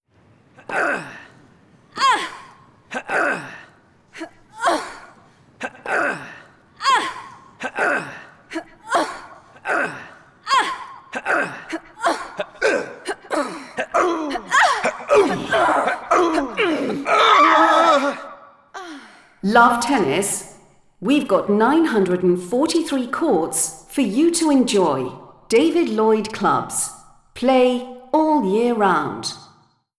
Tactical radio commercial for true lovers of tennis. Those people for whom Wimbledon is a climax of the tennis season.